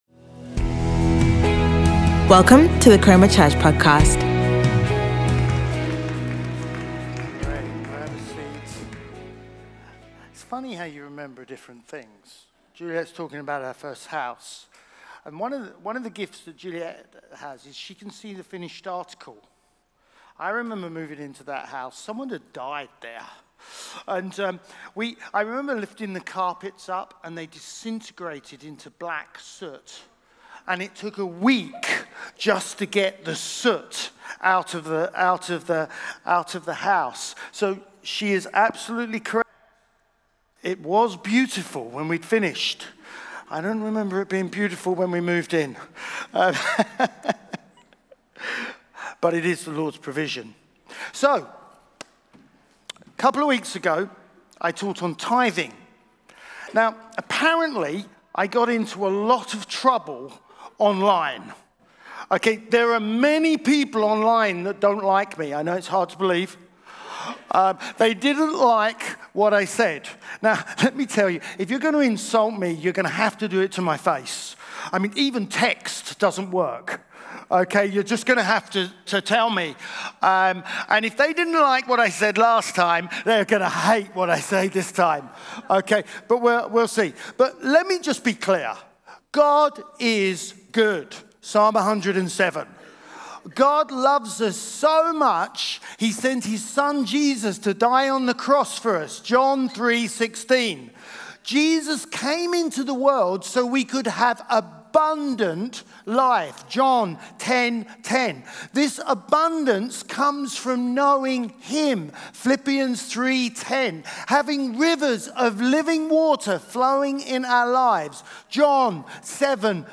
Chroma Church - Sunday Sermon What Seed ?